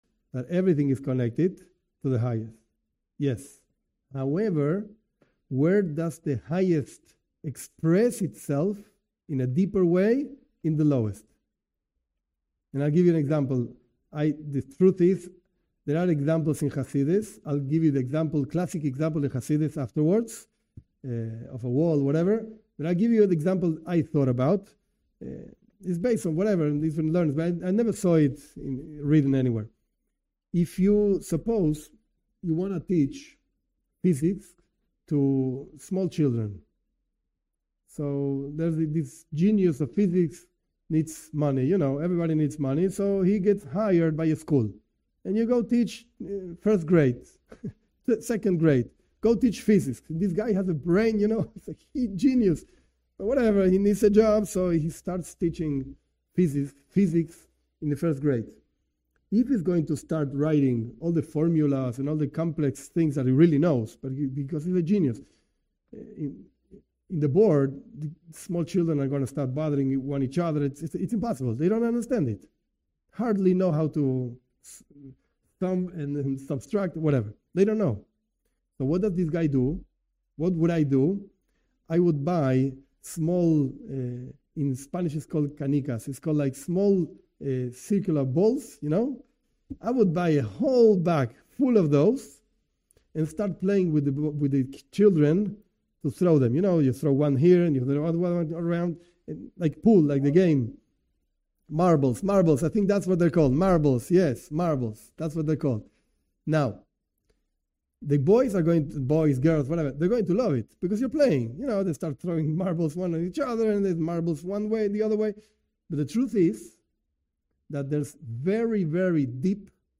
This part of a class presents an explanation.